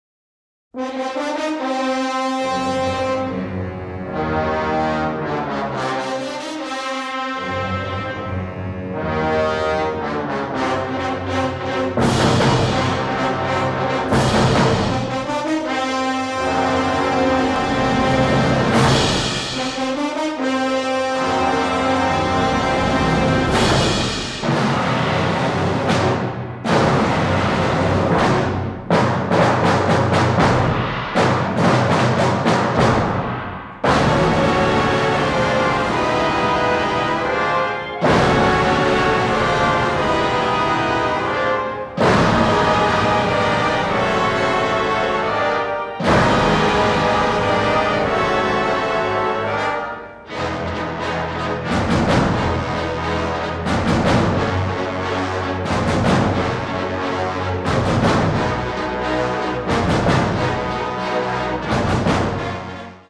augmented by a large brass and percussion section